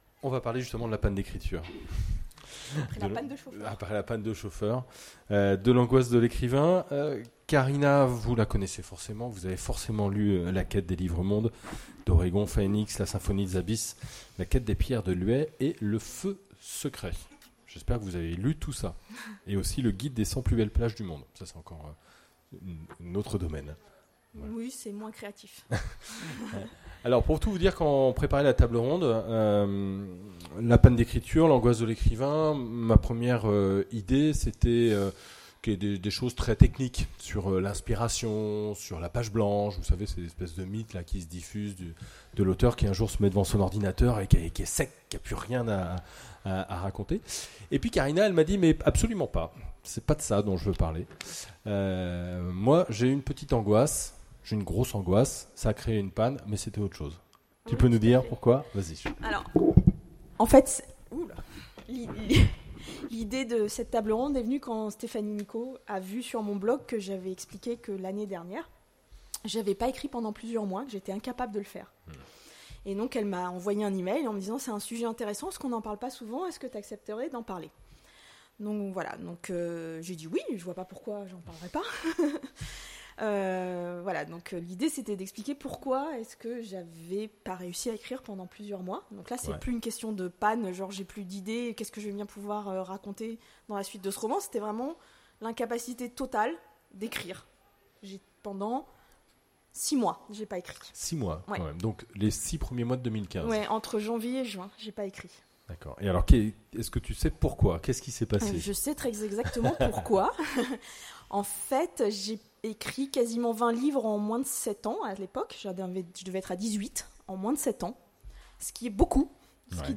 Imaginales 2016 : Conférence La panne d’écriture…